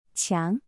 (qiáng) — wall